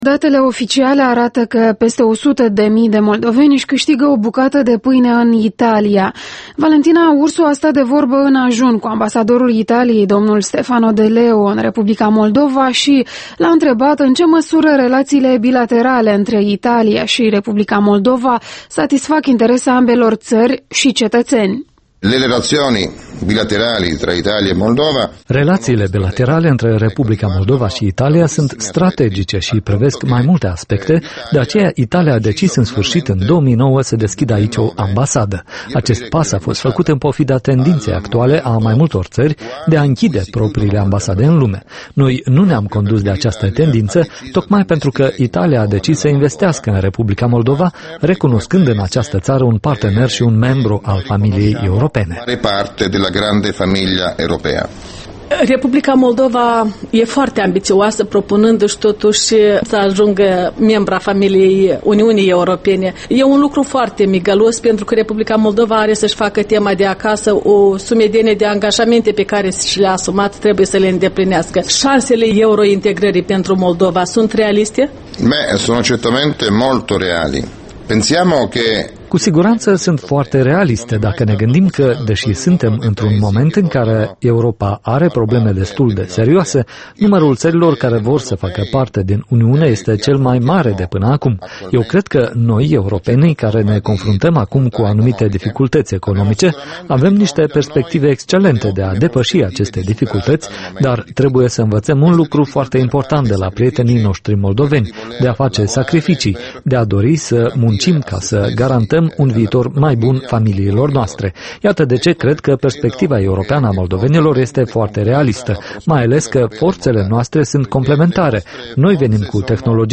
Interviul dimineții la Europa Liberă: cu Stefano de Leo, ambasadorul Italiei